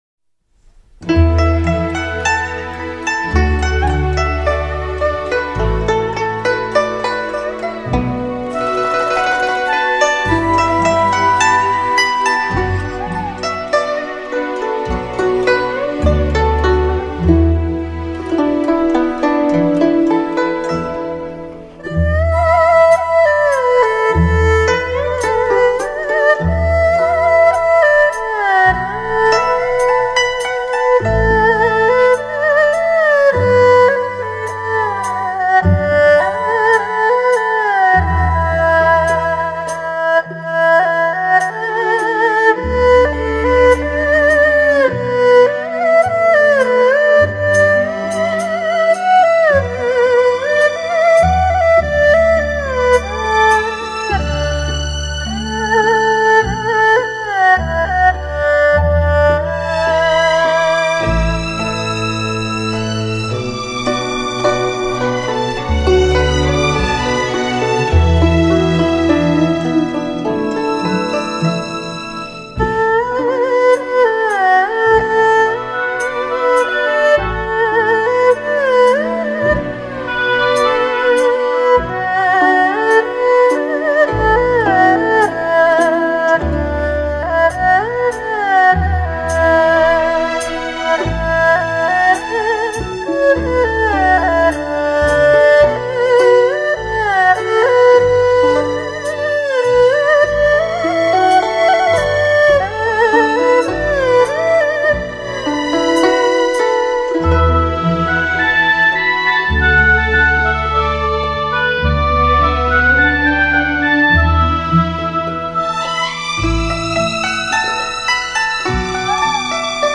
音乐类型: 民乐
中国的拉弦乐器之代表——二胡，以可歌、可诉、可愤、可吟的音色，演奏了许多优秀名曲。